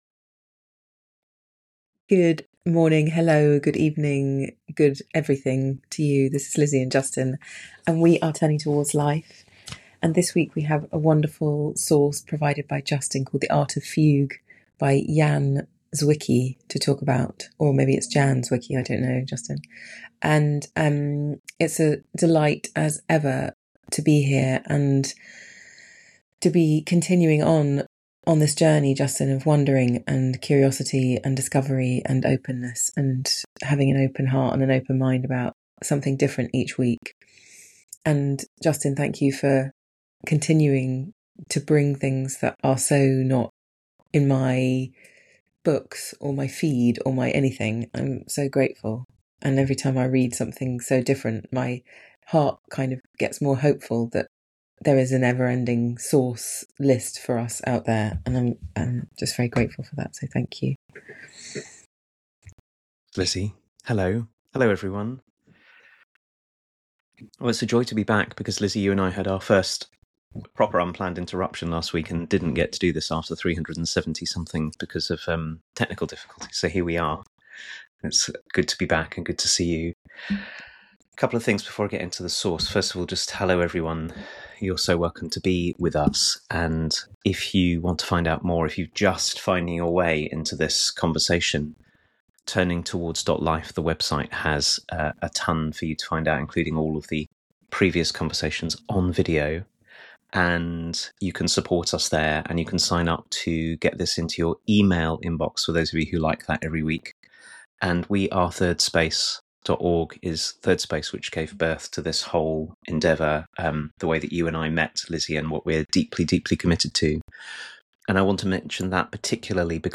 Turning Towards Life, a week-by-week conversation inviting us deeply into our lives, is a live 30 minute conversation